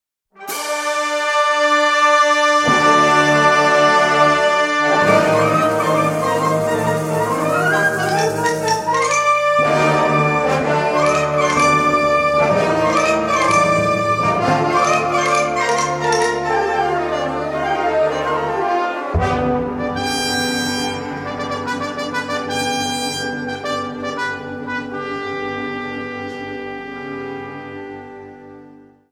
Categorie Harmonie/Fanfare/Brass-orkest
Bezetting Ha (harmonieorkest)